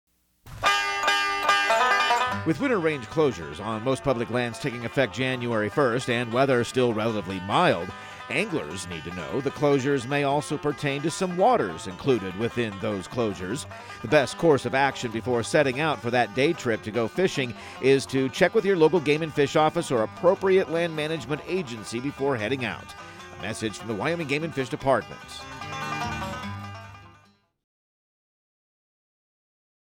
Outdoor Tip PSA